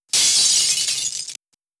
Glass BreakSound.wav